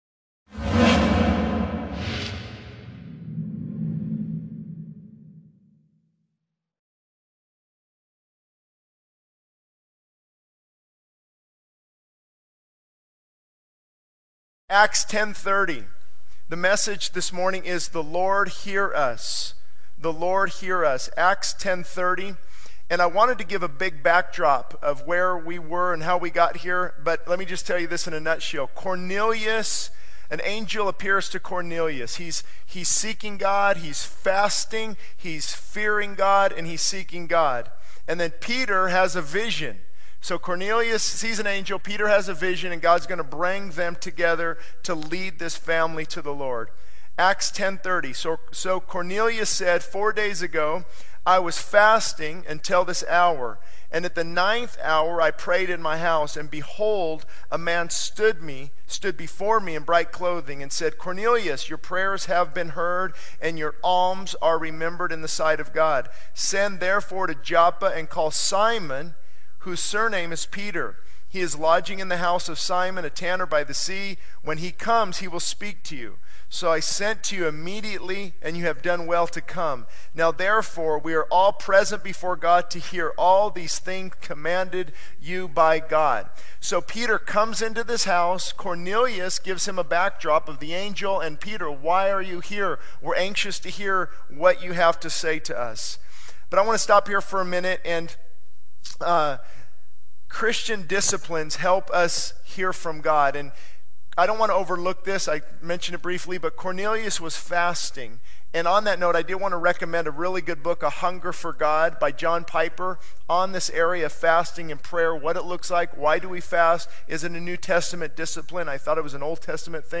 This sermon from Acts 10:30 emphasizes the importance of God hearing our prayers. It highlights the story of Cornelius seeking God and Peter's vision, showing how God brings them together. The sermon delves into the power of Christian disciplines like fasting and prayer to help us hear from God.